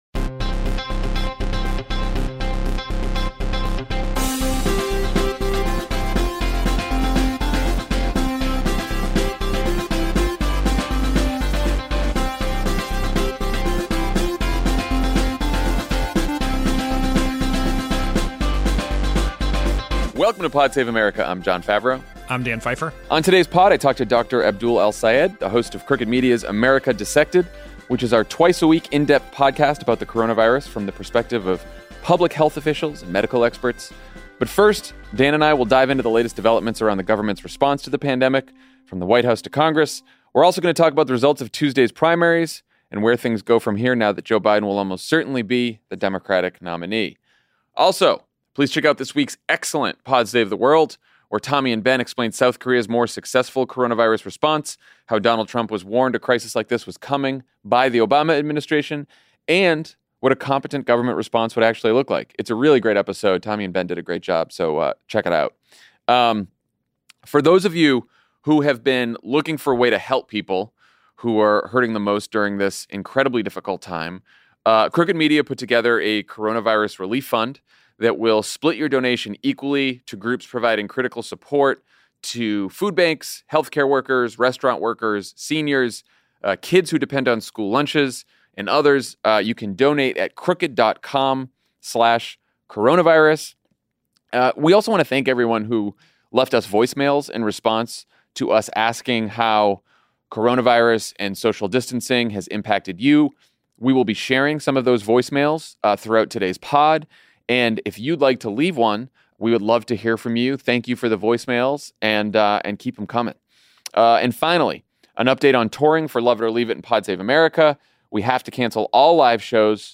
Trump’s failures put the U.S. behind the rest of the world on testing, Congress debates the size and scope of the next economic relief package, and Joe Biden moves closer to clinching the Democratic nomination. Then Dr. Abdul El-Sayed, host of Crooked Media’s America Dissected, answers some of your questions about the coronavirus pandemic.